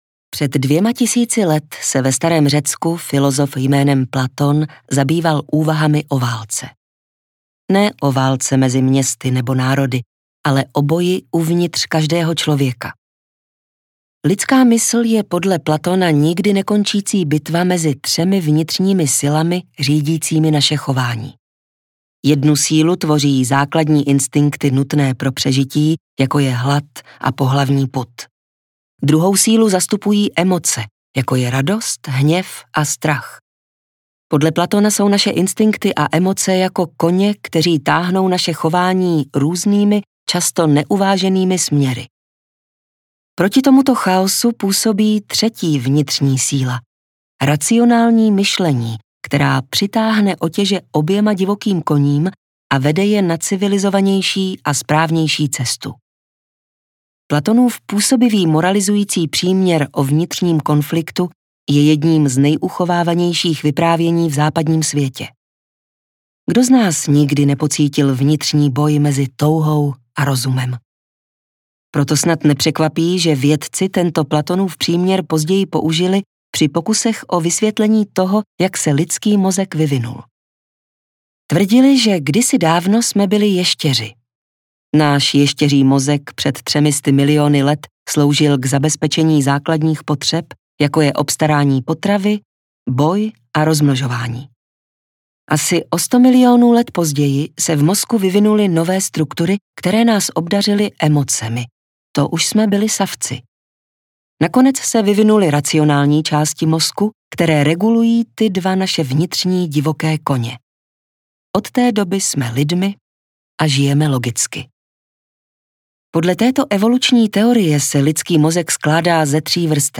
Sedm a půl kapitoly o mozku audiokniha
Ukázka z knihy